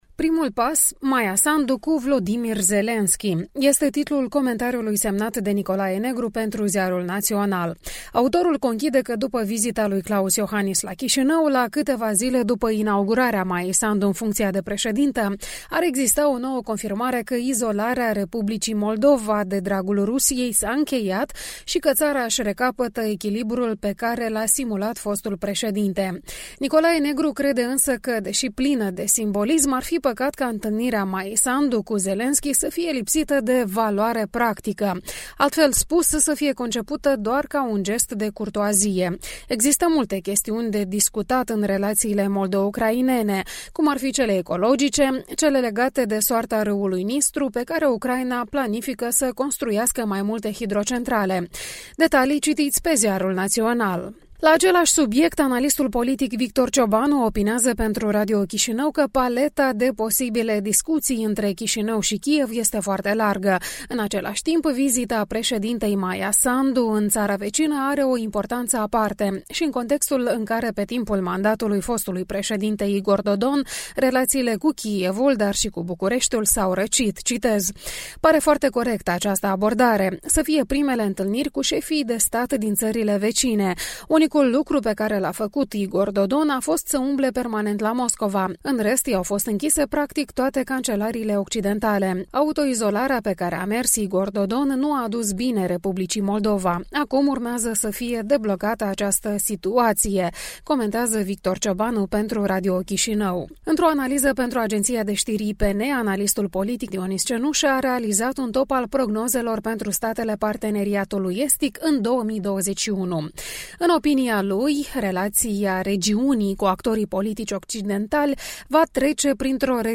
Revista matinală a presei